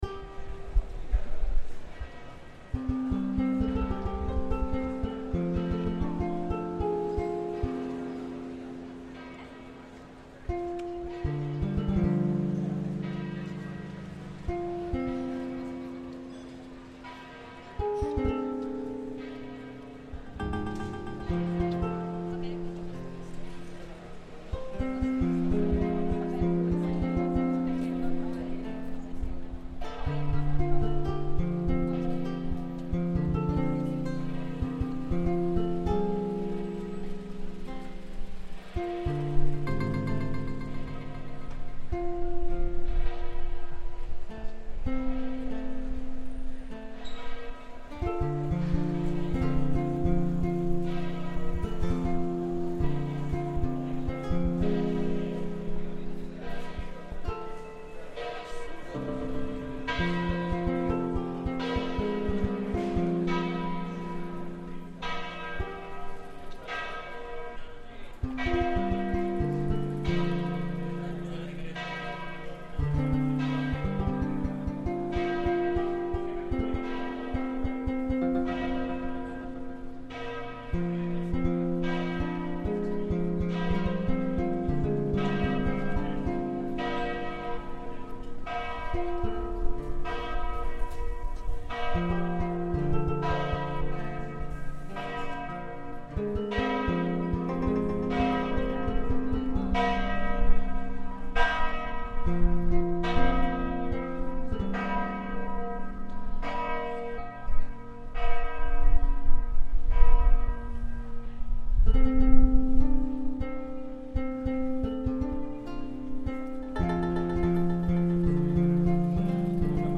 Midday bells in Treviso reimagined